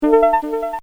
watch1.mp3